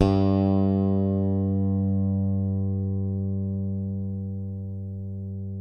WARW.FING G2.wav